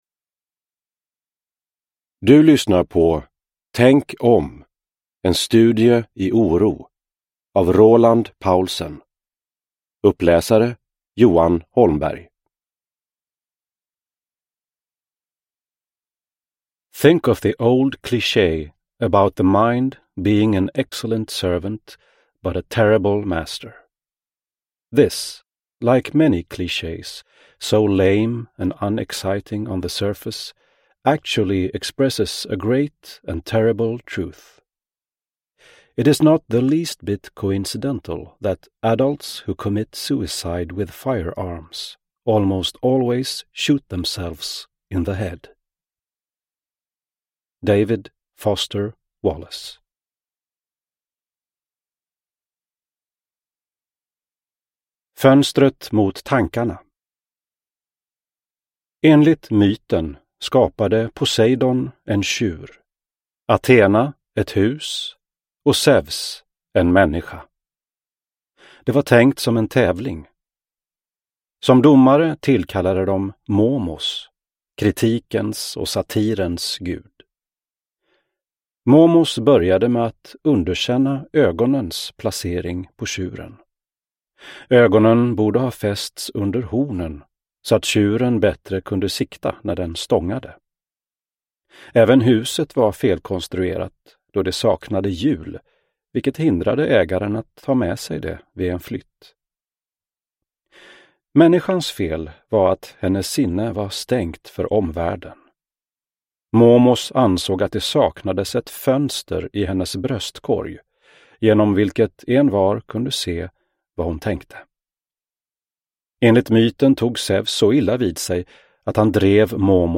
Tänk om : en studie i oro – Ljudbok – Laddas ner